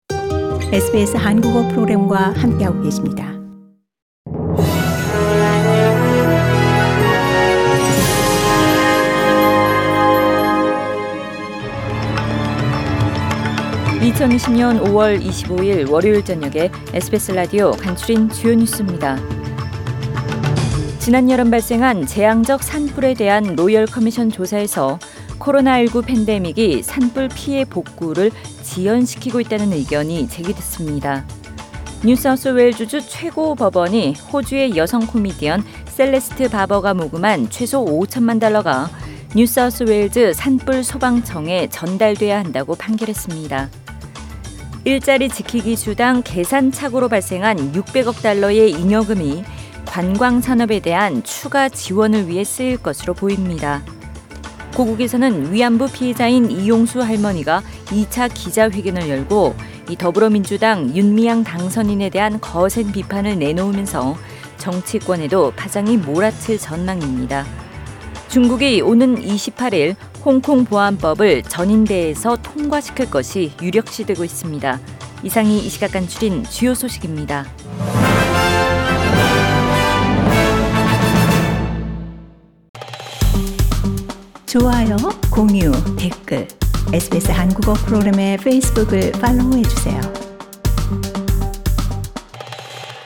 SBS 한국어 뉴스 간추린 주요 소식 – 5월 25일 월요일